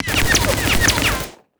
sfx_skill 02_2.wav